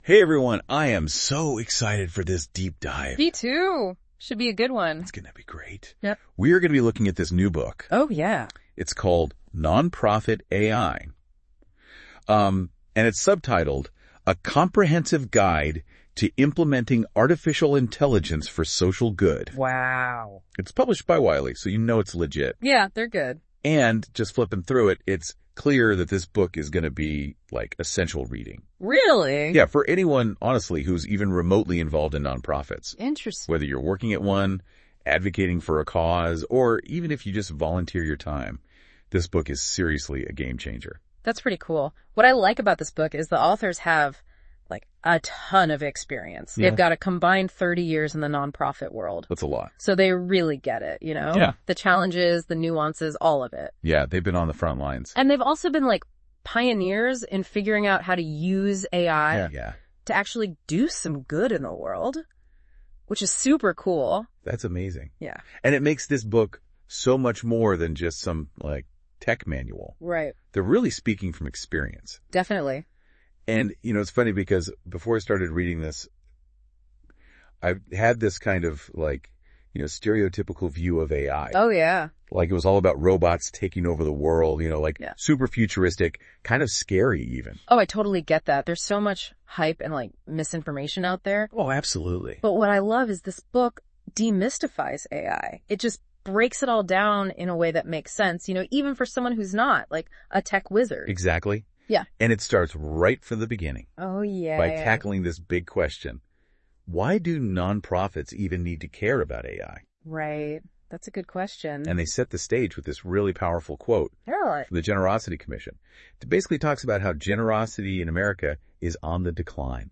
This guide explores practical strategies for leveraging AI responsibly, enhancing fundraising, streamlining operations, and driving meaningful social impact in an increasingly digital world. AI Generated Audio Overview